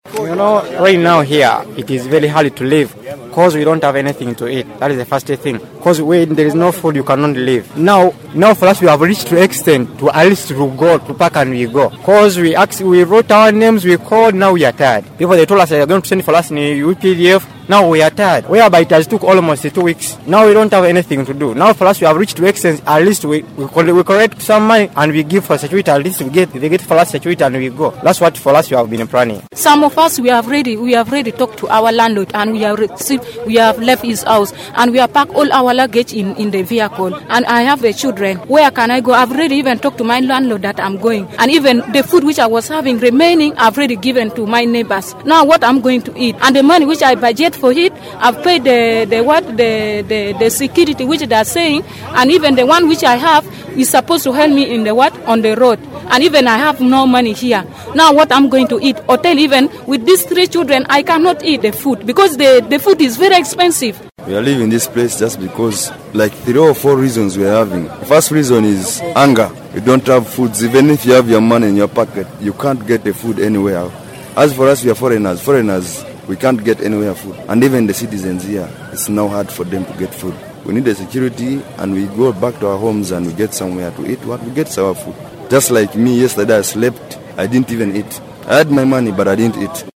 Here are their voices